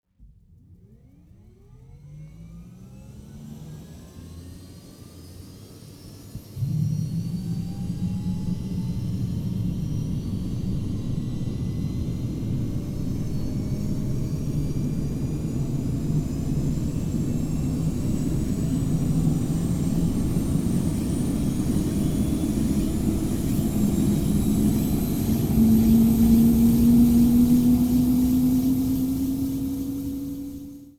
engine_startup_interior.wav